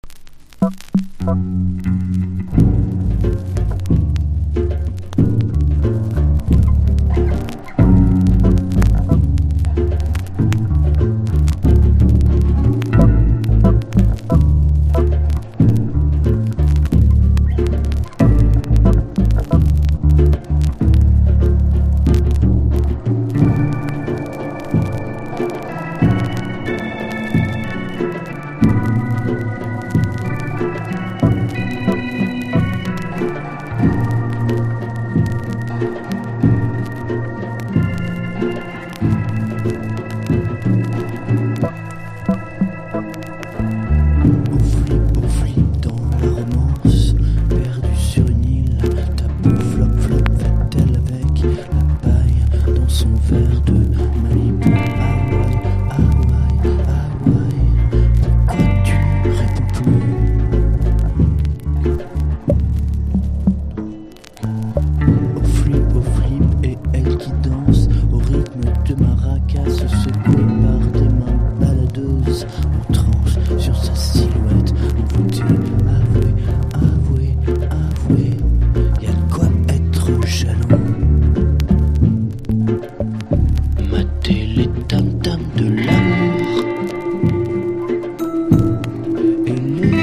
# NEO ACOUSTIC / GUITAR POP# 90’s ROCK
北半球に暮らす音楽的ボヘミアンによる、赤道越えで時空を歪めてしまったトロピカル音楽。